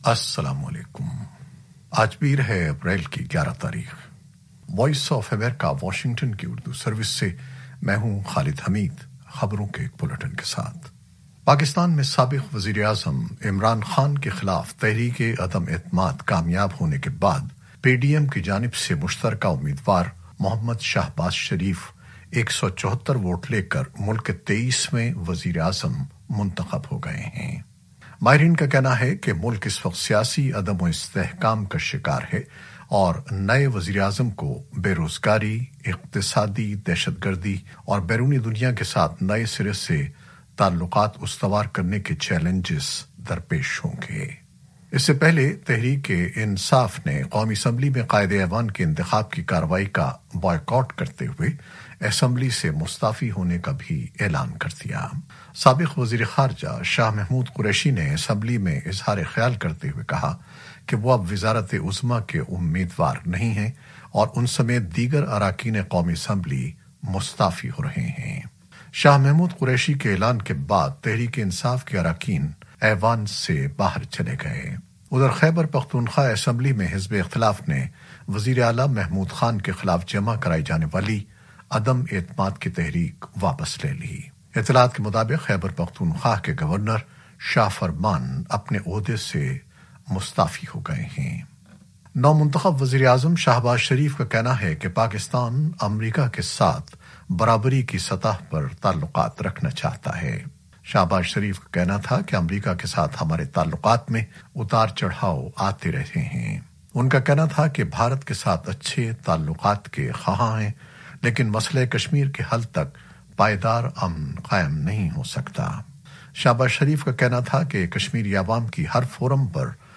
نیوز بلیٹن 2021-11-04